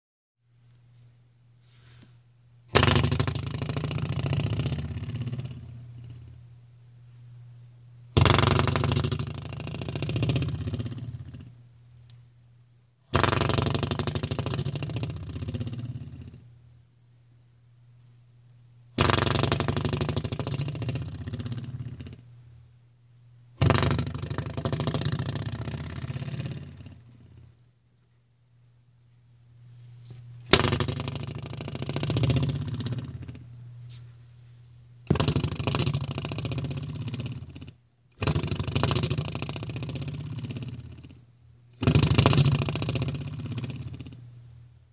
门把手和锁
描述：抖动门把手的手柄。另外，在同一扇门上锁和解锁死锁。室内。用Zoom H2
标签： 插销 死了 门栓 现场记录 处理
声道立体声